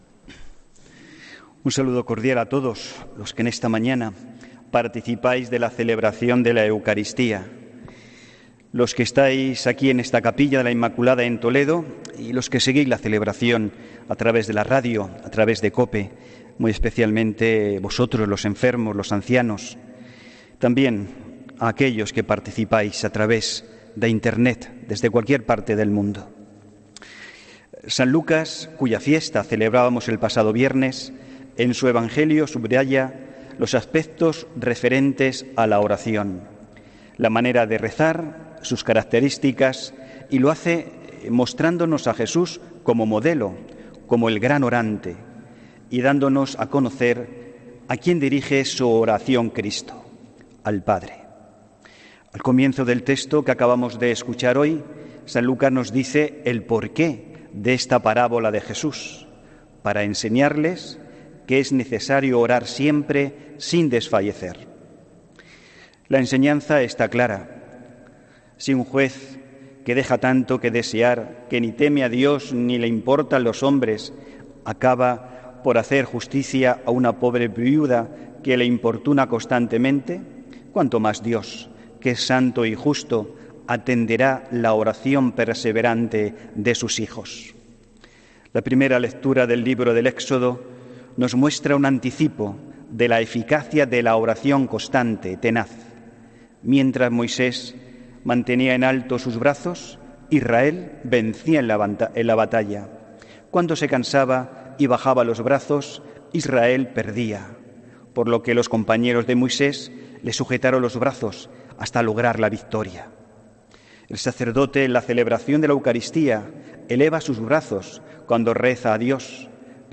HOMILÍA 20 OCTUBRE 2019